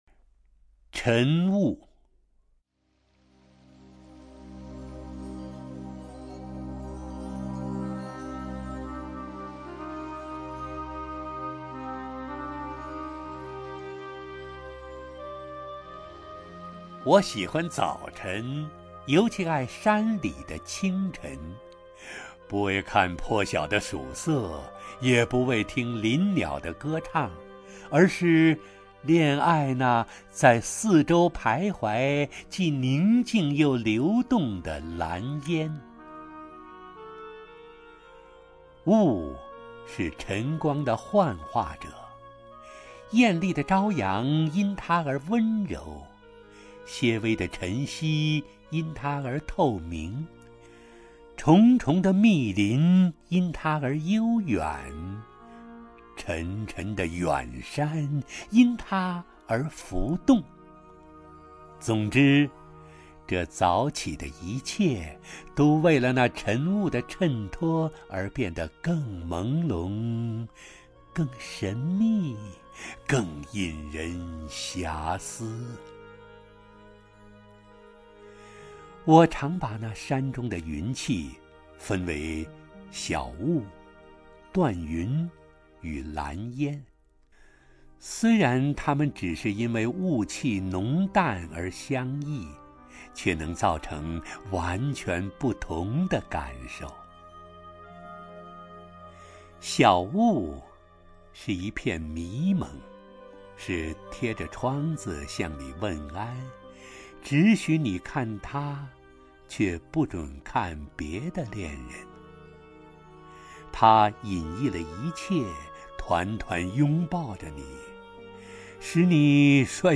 首页 视听 名家朗诵欣赏 陈醇
陈醇朗诵：《晨雾》(刘墉)